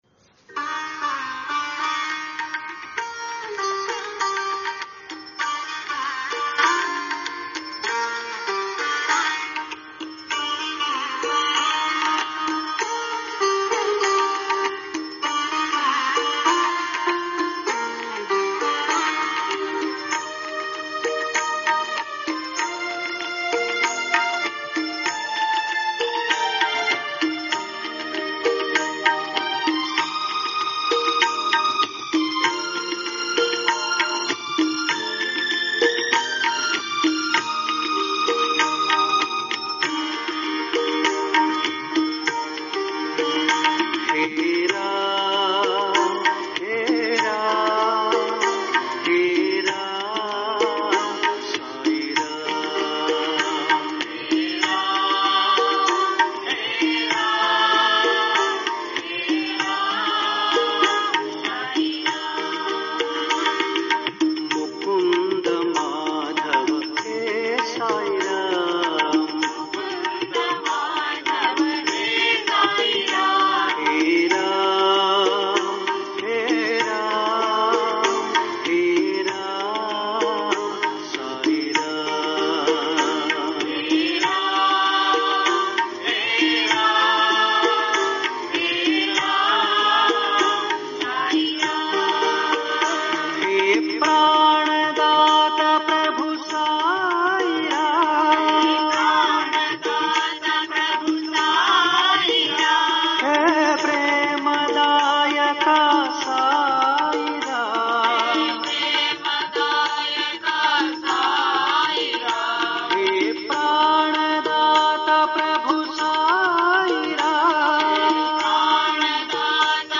Related Bhajan